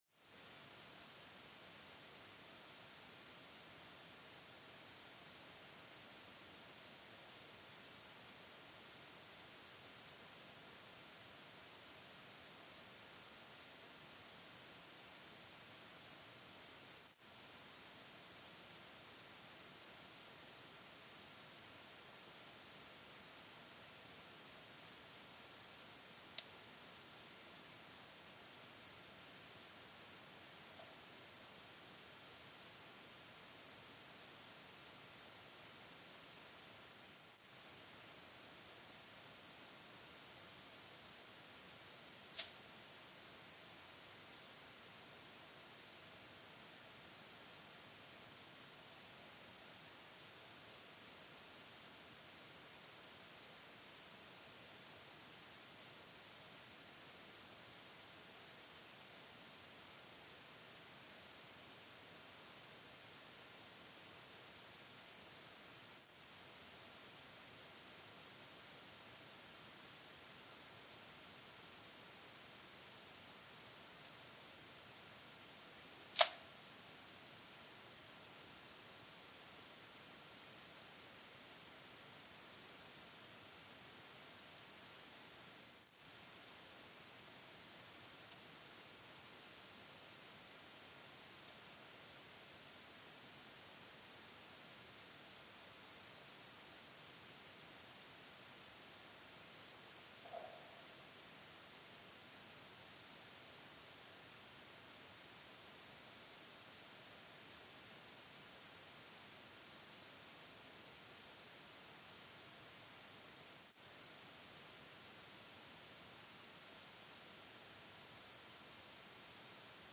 Mbarara, Uganda (seismic) archived on December 21, 2024
Sensor : Geotech KS54000 triaxial broadband borehole seismometer
Speedup : ×1,800 (transposed up about 11 octaves)
Loop duration (audio) : 05:36 (stereo)